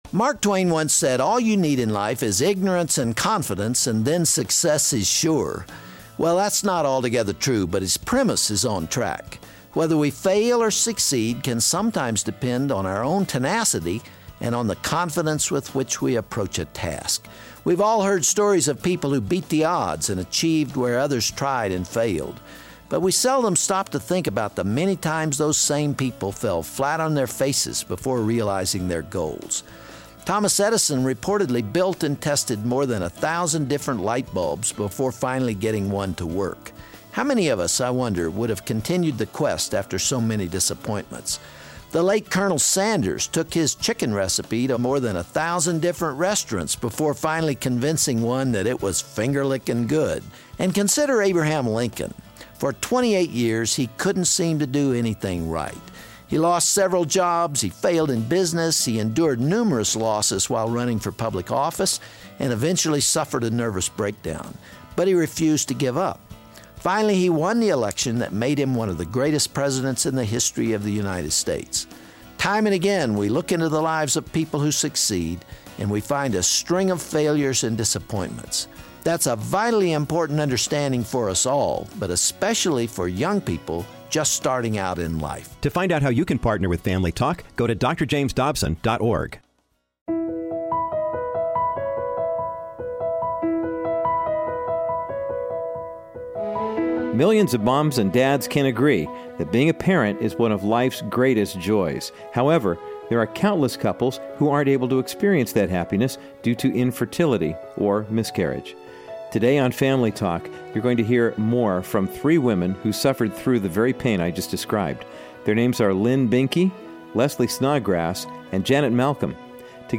On this Family Talk broadcast, a panel of women continue sharing with Dr. Dobson their hardships with miscarriage and infertility. They explain how their dreams of creating baby announcements and holding a new life in their arms were often met with hopelessness.